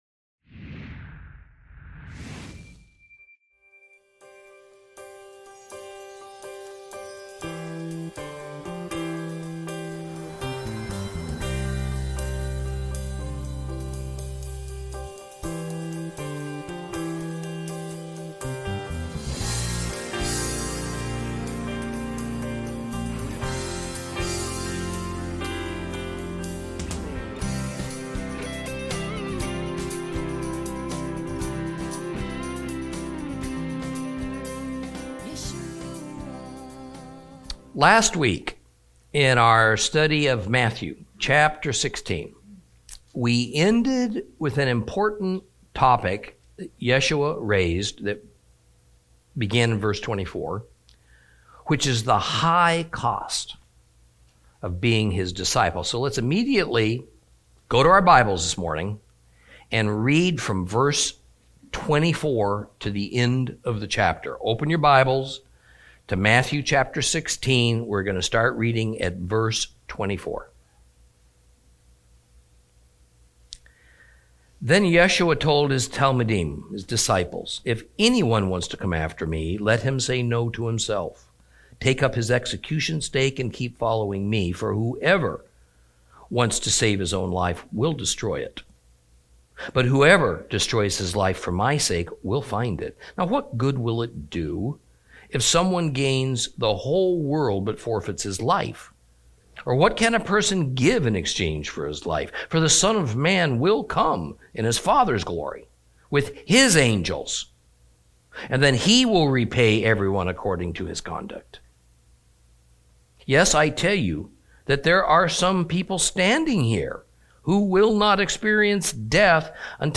Lesson 59 Ch16 Ch17 - Torah Class